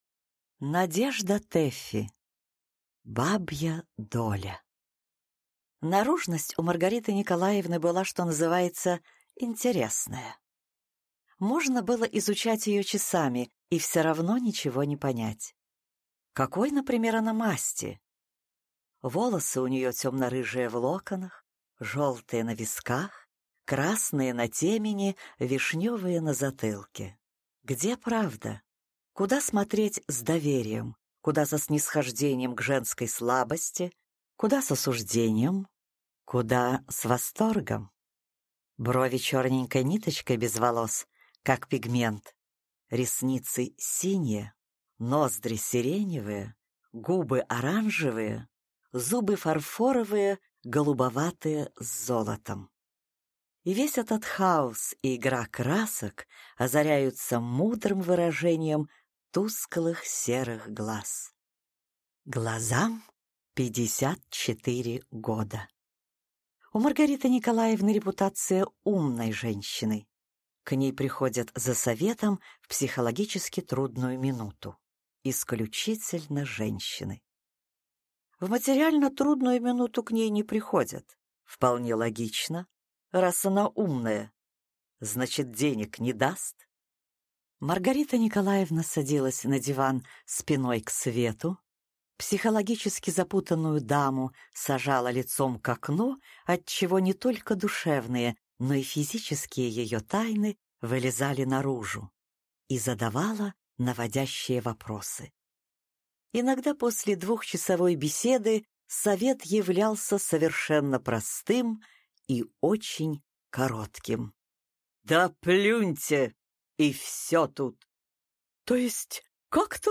Аудиокнига Бабья доля | Библиотека аудиокниг